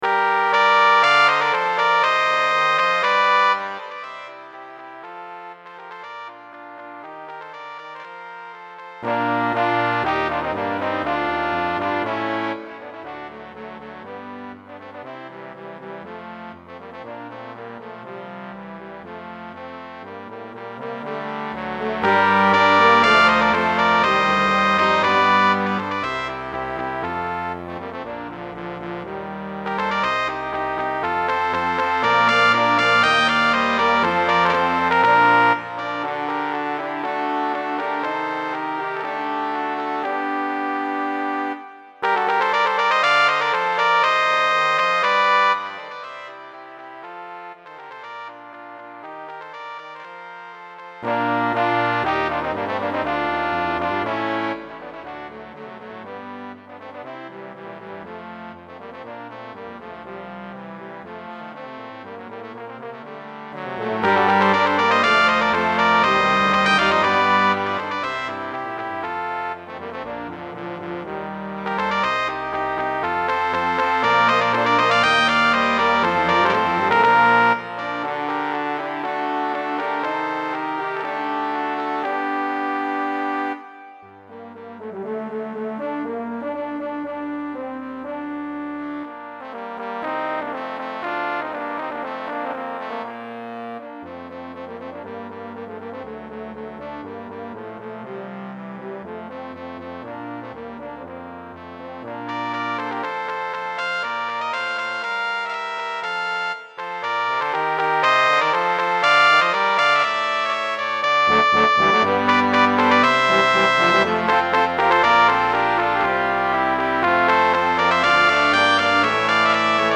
Baroque in style